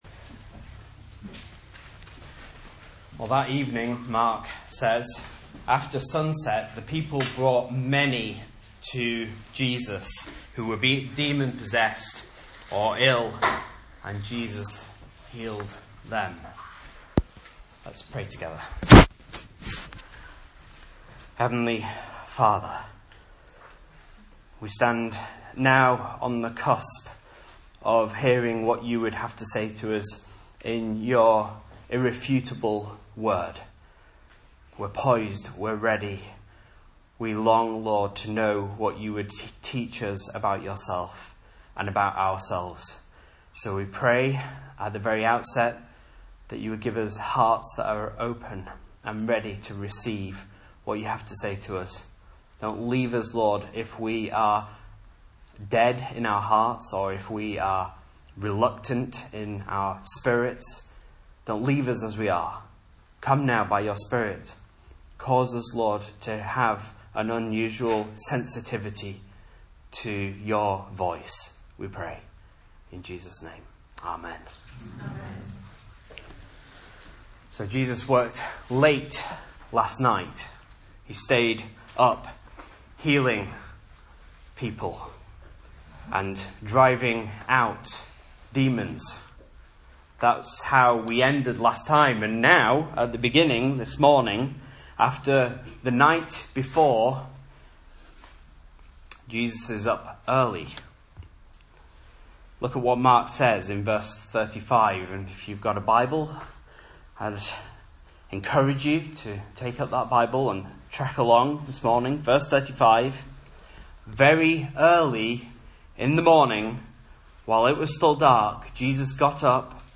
Message Scripture: Mark 1:35-2:12